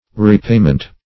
Repayment \Re*pay"ment\ (-ment), n.